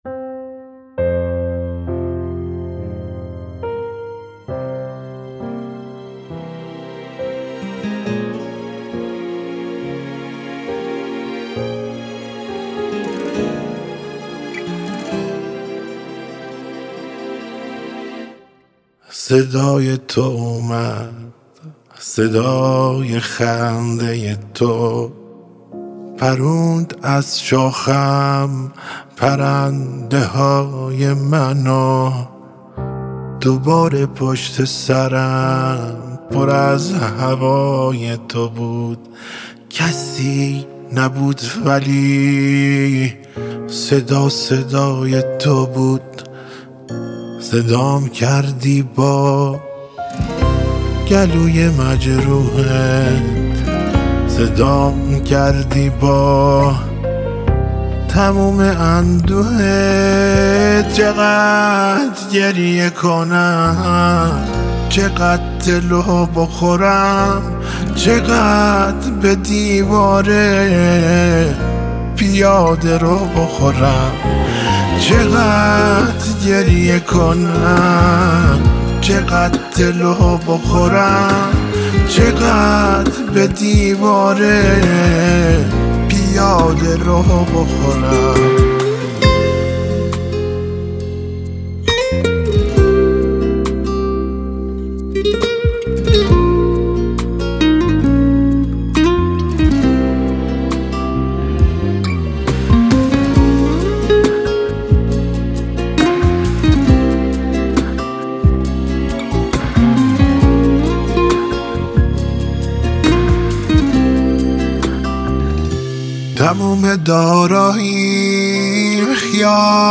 دانلود موسیقی موسیقی ایرانی